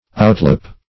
outlope - definition of outlope - synonyms, pronunciation, spelling from Free Dictionary Search Result for " outlope" : The Collaborative International Dictionary of English v.0.48: Outlope \Out"lope\, n. An excursion.